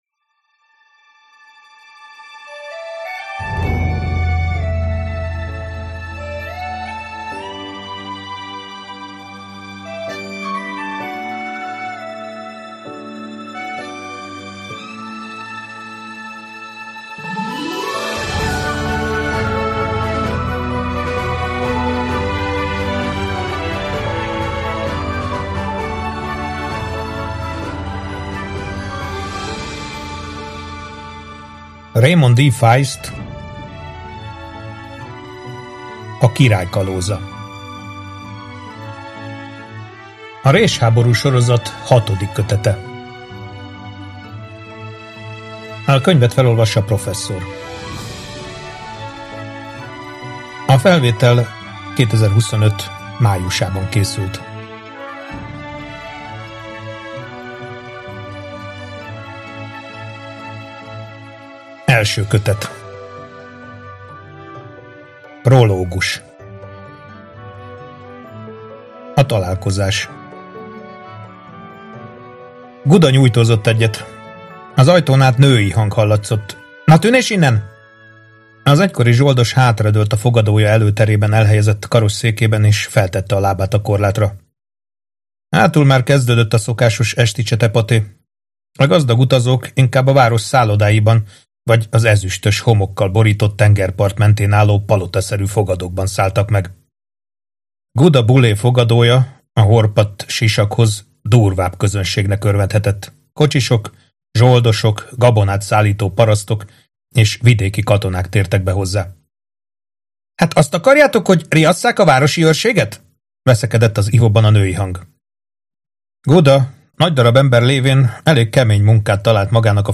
A tanítvány Hangoskönyv A Résháború sorozat 1. része Előadja